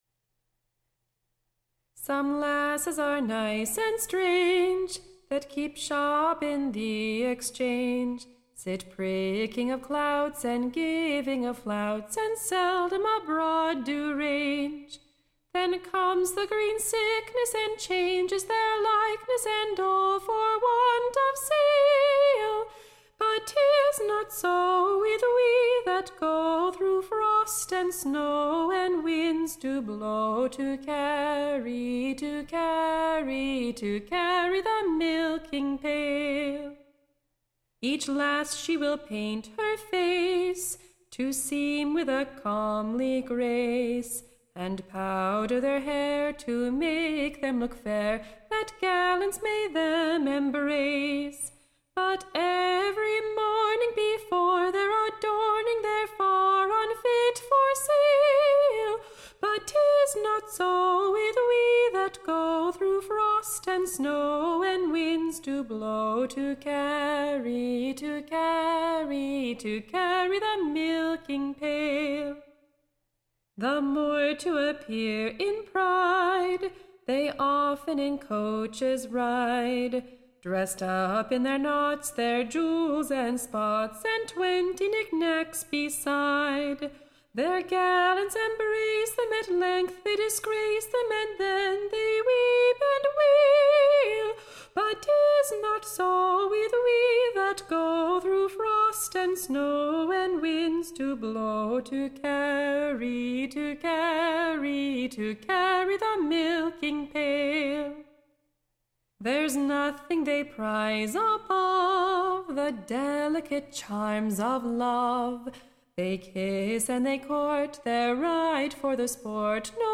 Tune Imprint Set to an Excellent Country Dance.